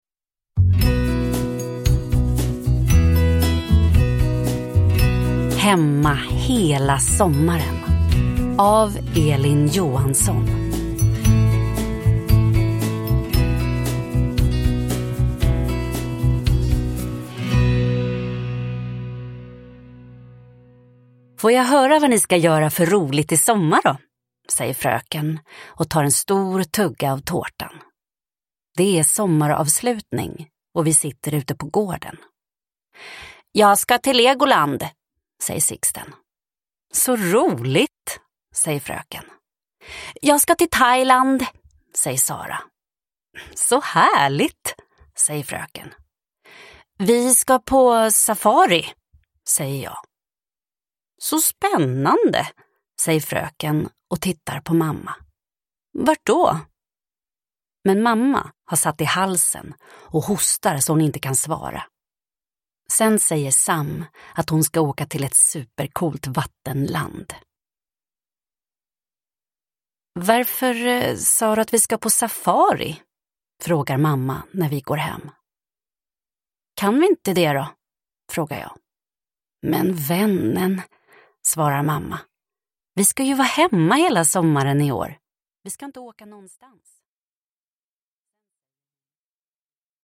Hemma hela sommaren – Ljudbok – Laddas ner